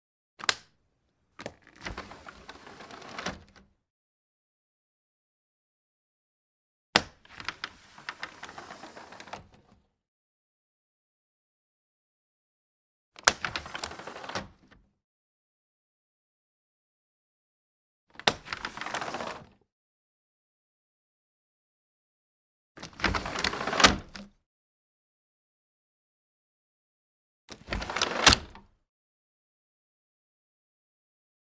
Plastic Vinyl Sliding Window Unlatch Open Sound
household